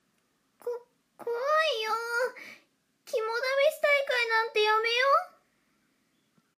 サンプルボイス 気弱 【少女】